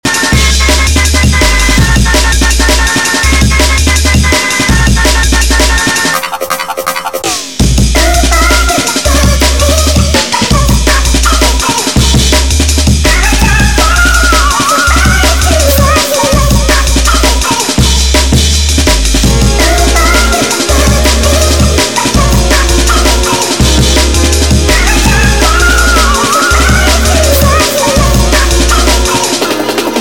165 To 134 BPM Extended Mix